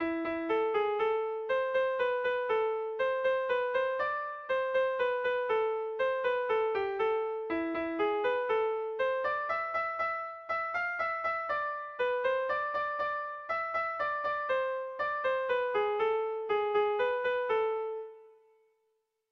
Bertso melodies - View details   To know more about this section
Sei puntuko berdina, 10 silabaz
10A / 10A / 10A / 10A / 10A / 10A
ABDEFG